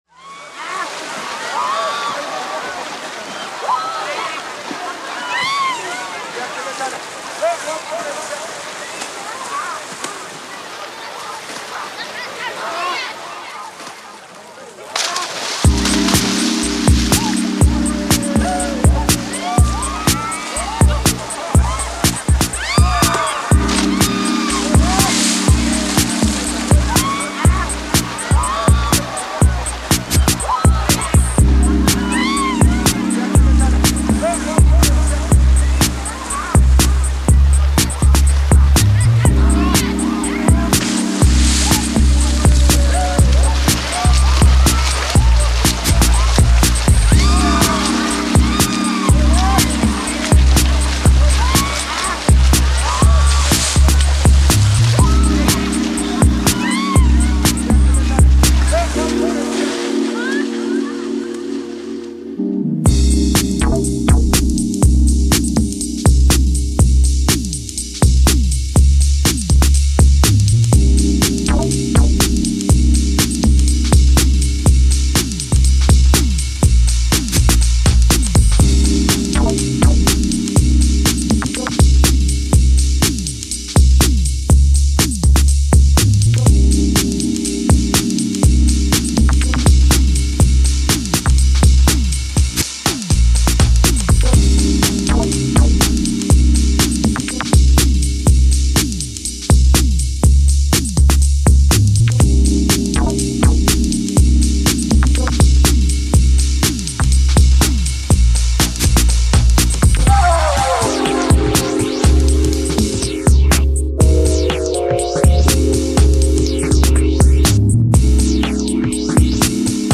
BPM61-122
Audio QualityPerfect (Low Quality)